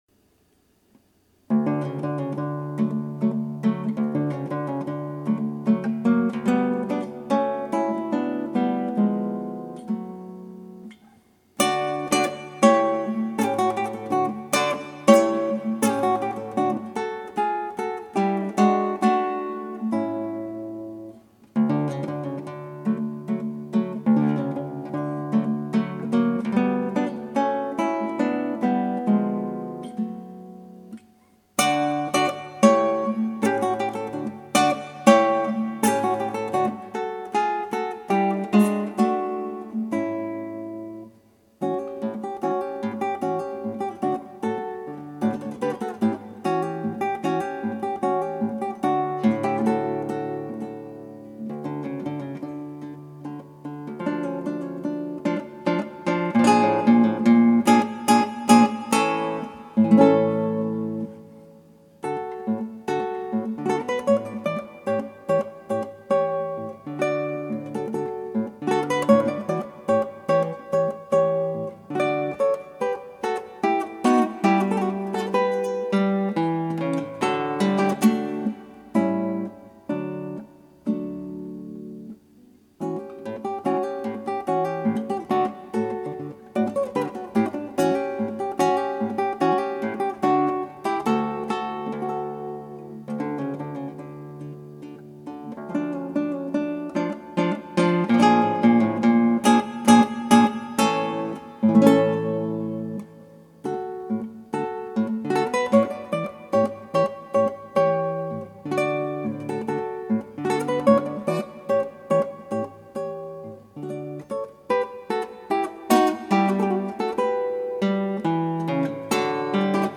クラシックギター 「小品など・・・」 - 「メヌエット」
ギターの自演をストリーミングで提供
「小品など・・・」 「メヌエット」 06/2/5 昔はこういう曲がギタースタンダードだったのです。 バスの消音が難しいかな・・。